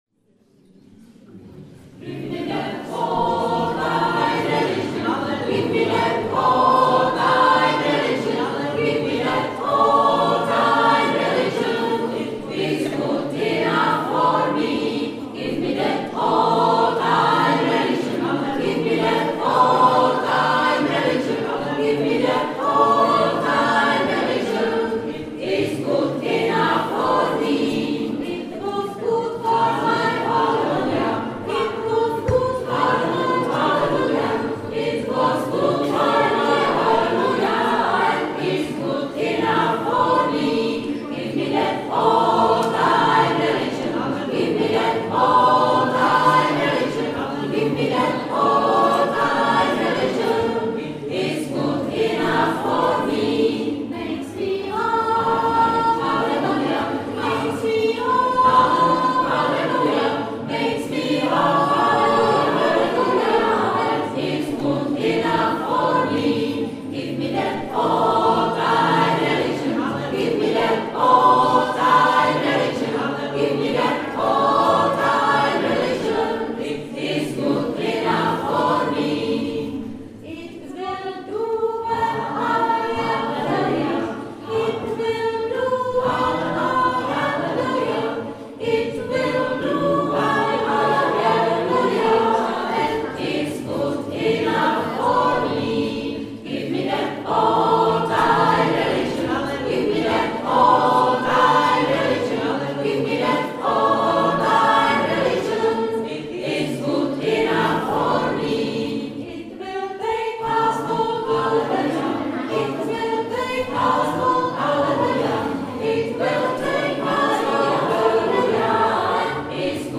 16.1.2009 Vernisáž dětských prací - sál ZUŠ ve Zlivi